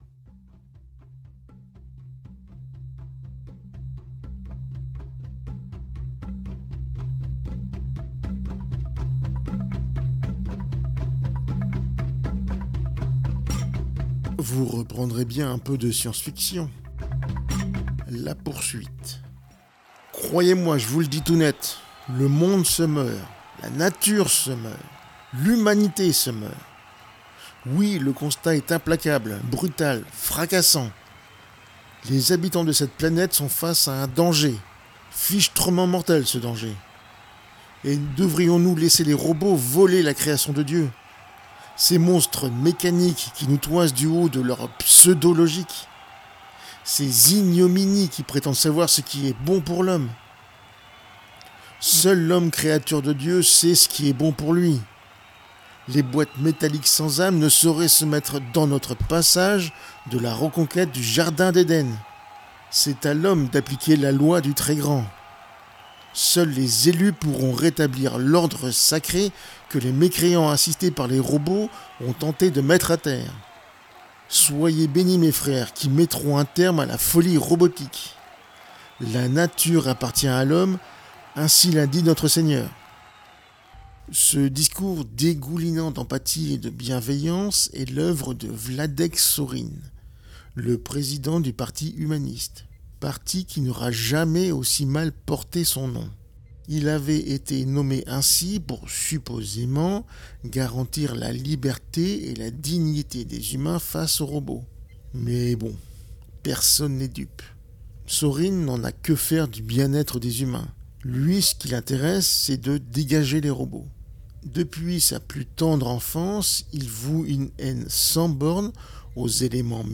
Aujourd’hui c’est fiction